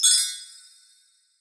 chimes_magic_bell_ding_5.wav